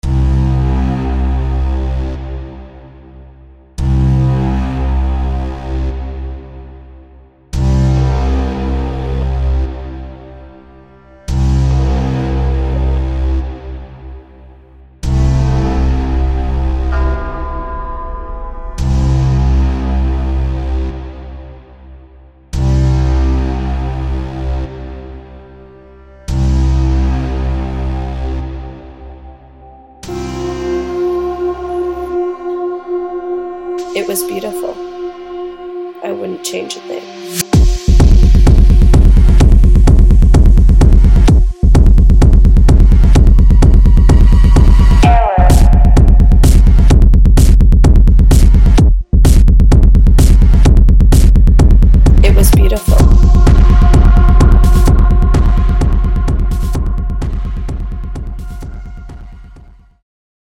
Trap
versatile bass, drum, vocal, and synth samples